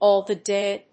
アクセントáll dáy (lóng)＝áll the dáy